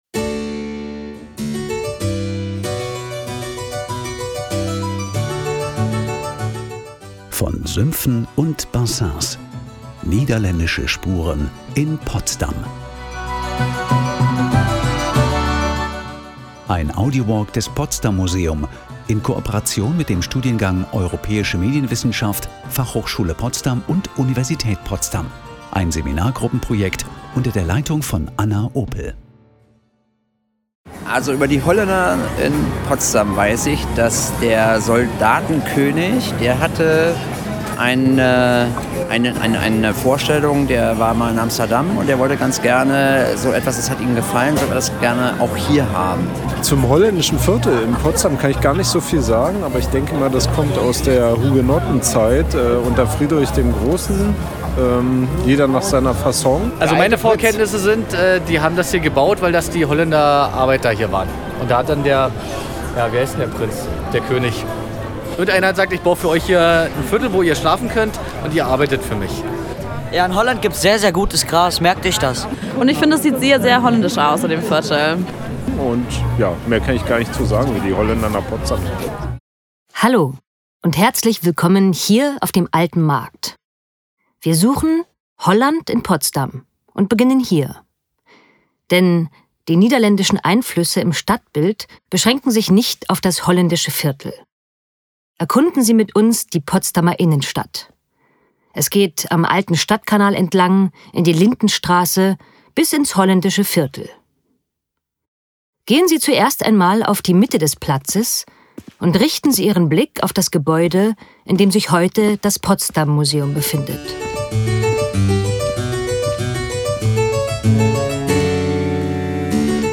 Der AudioWalk „Von Sümpfen und Bassins“ ist ein durchgängiger Hörspaziergang durch den Stadtraum.